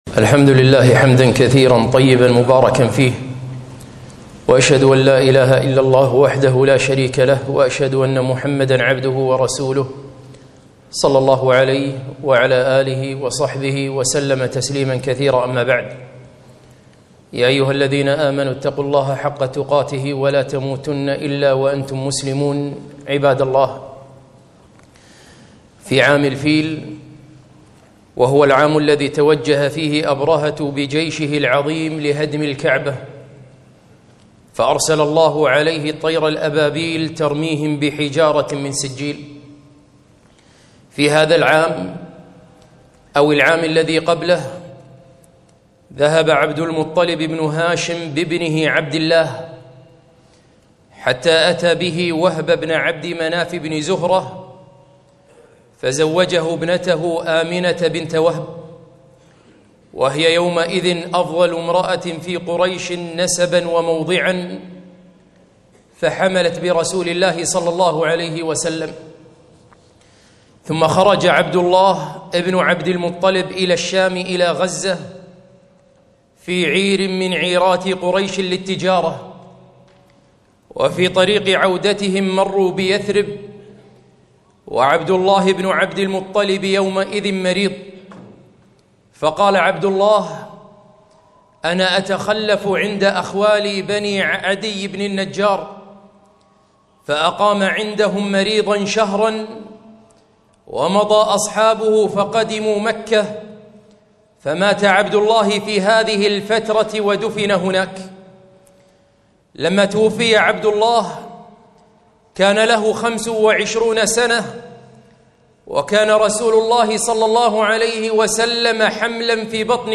خطبة - لماذا نحتفل بالمولد النبوي؟